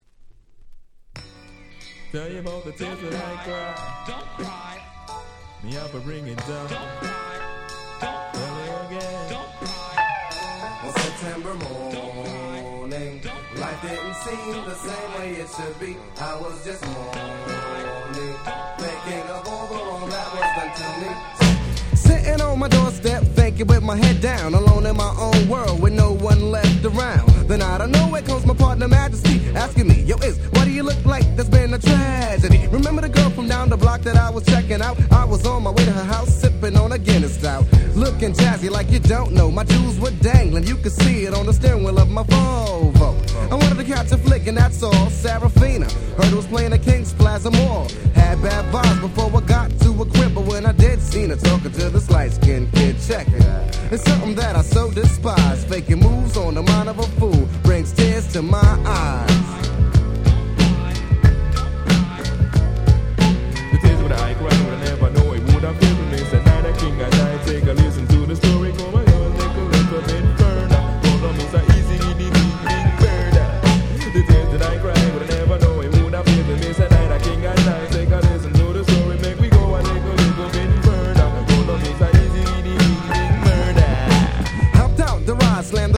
93' Super Nice Hip Hop / Boom Bap !!